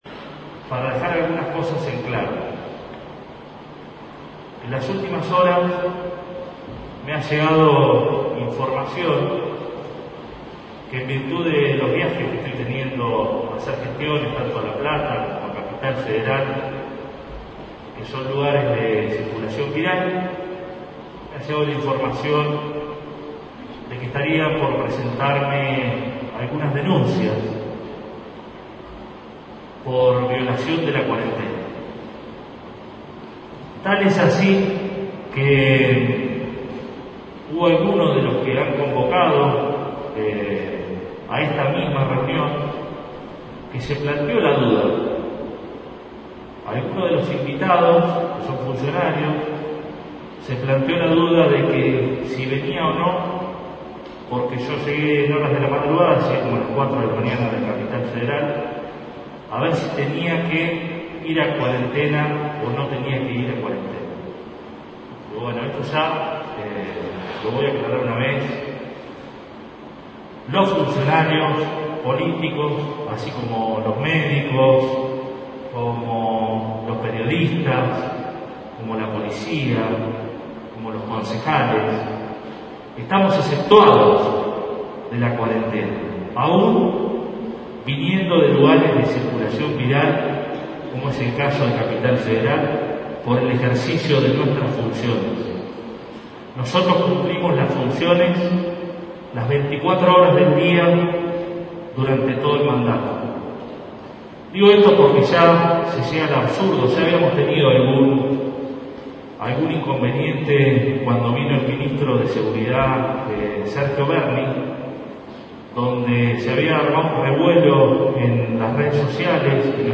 La declaración llegó durante la apertura del ampliado Comité de Emergencia y Crisis Municipal, que se desarrolló esta tarde en el Salón de Actos de la comuna con la presencia de representantes de distintos sectores, y ante rumores que circularon en distintos medios indicando que la principal autoridad municipal debería realizar un aislamiento luego de un viaje a una zona de riesgo.